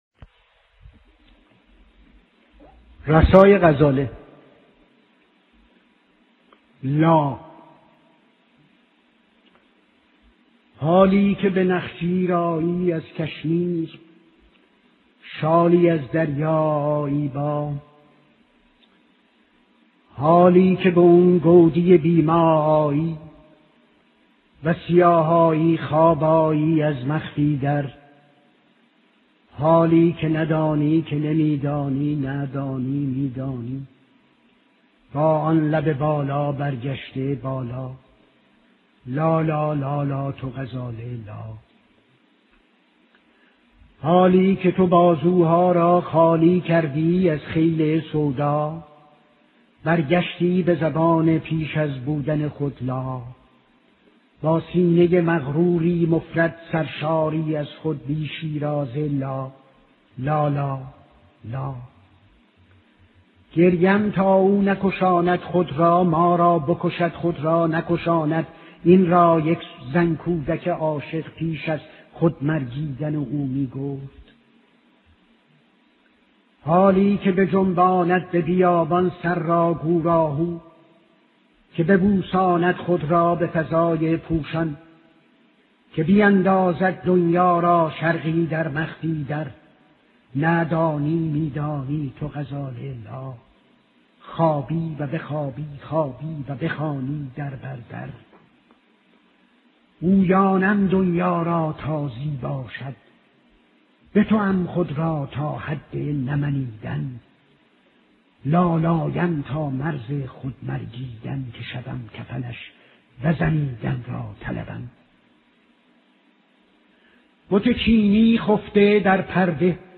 شعرخوانی‌های رضا براهنی